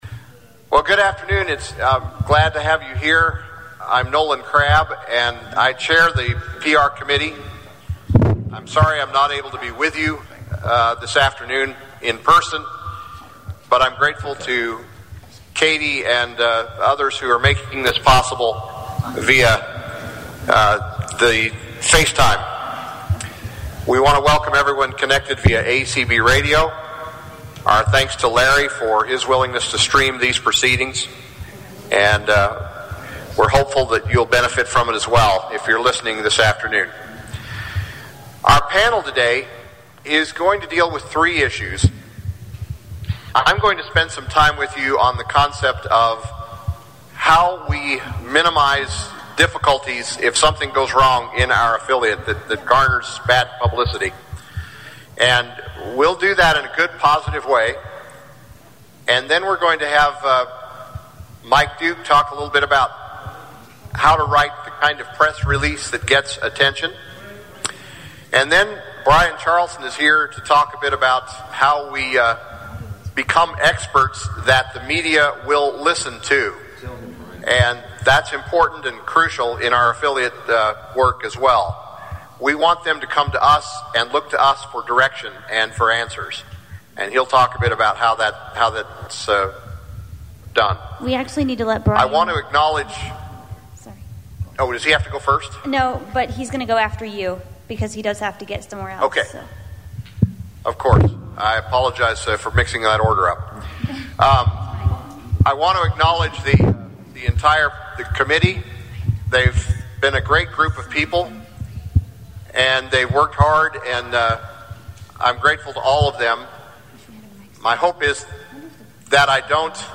Download the PR Basics workshop.